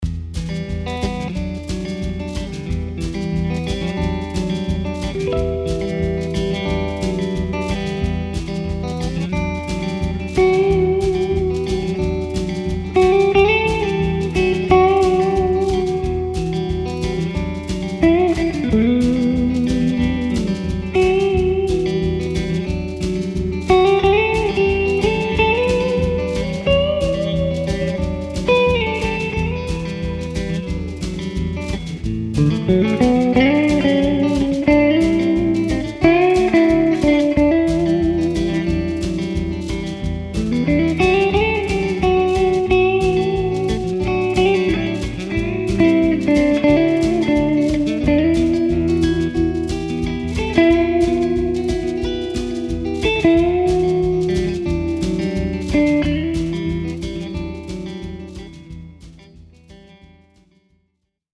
Here’s a clip I put together while playing around this evening that demonstrates how gorgeous the clean tones are:
I used my Strat for the rhythm part – amazingly in the middle pickup, which I’m really starting to love – and did the simple solo with my Prestige Guitars Heritage Elite with both pickups with about 60/40 mix of bridge and neck, respectively. The reverb you hear in the solo is the spring reverb in the Hot Rod.